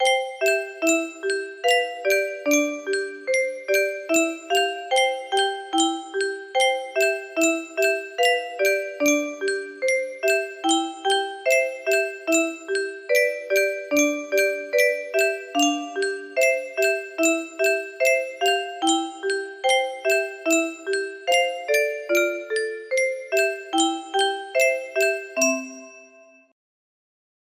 Butterfly music box melody